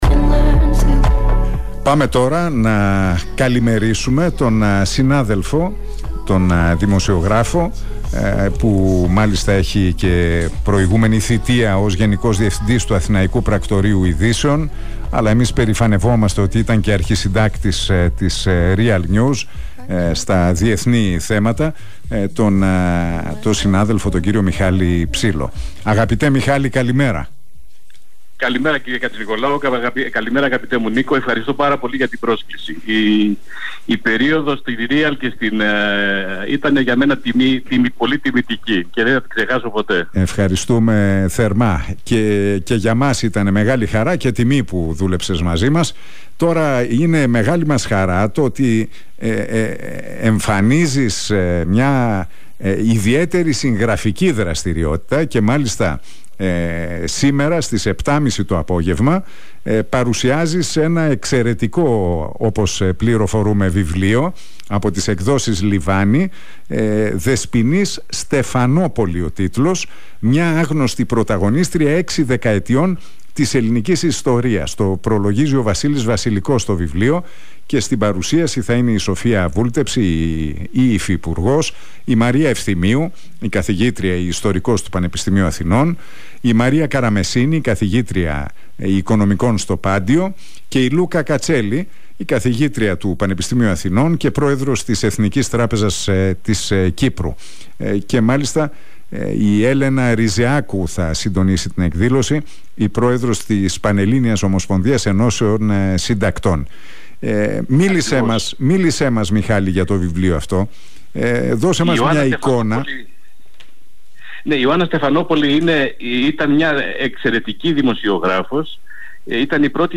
Ο δημοσιογράφος μίλησε στον Realfm 97,8 και στην εκπομπή του Νίκου Χατζηνικολάου για το “Δεσποινίς Στεφανόπολι”.